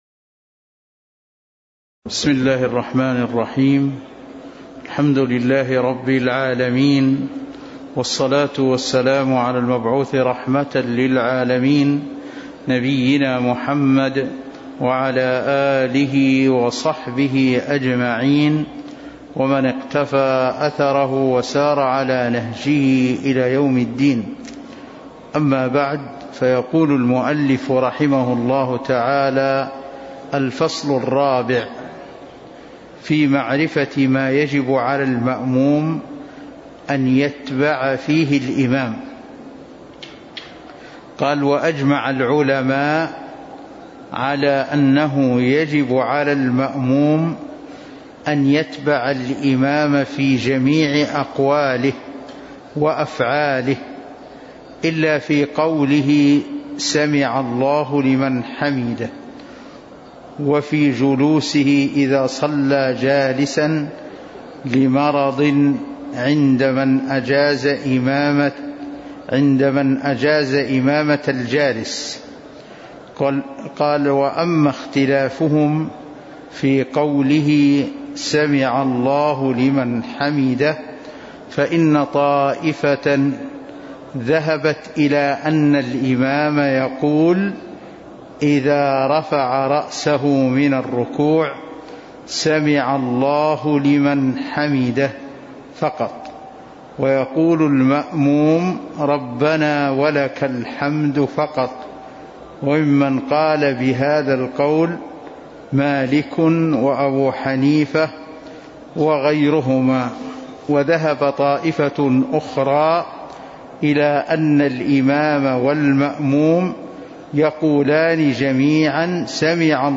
تاريخ النشر ٢ ربيع الثاني ١٤٤٣ هـ المكان: المسجد النبوي الشيخ